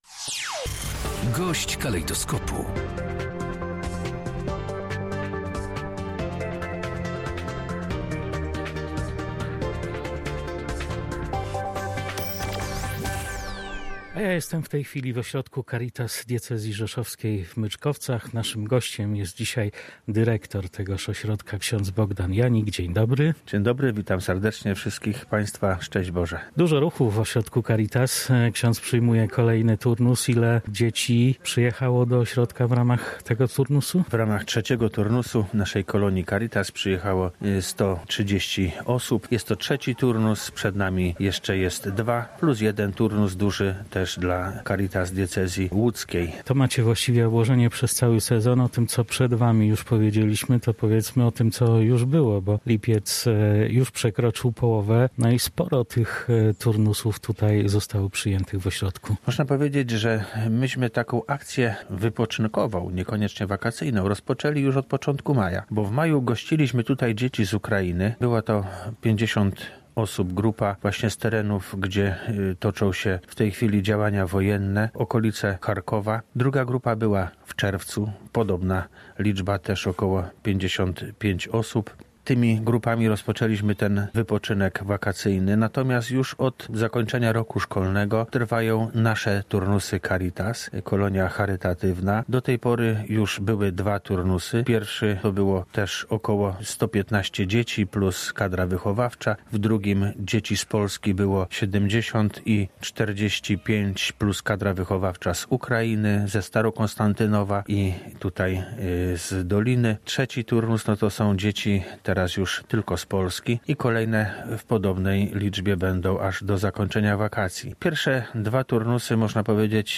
23_07_gosc_dnia.mp3